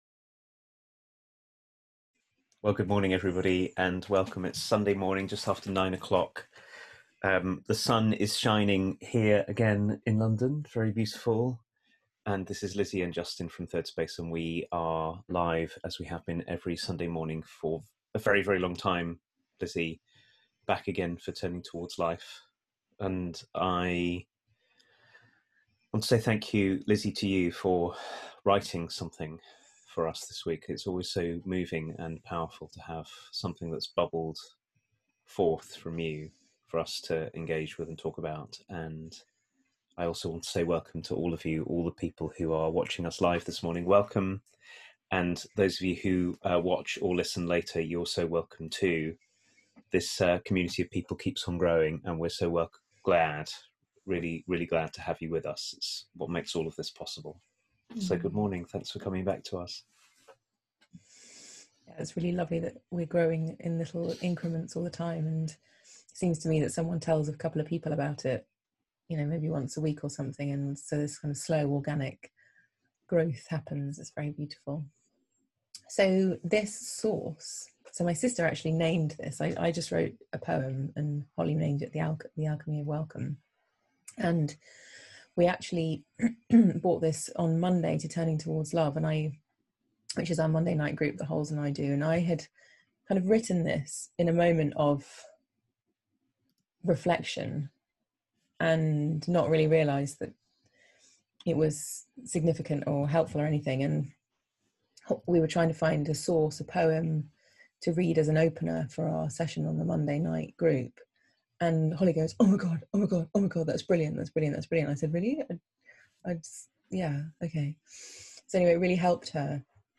And how our forgetting our own variety in this way also has us forget and not see the richness, depth and intricate variety of others. A conversation about welcoming ourselves and other people in our inner and outer complexity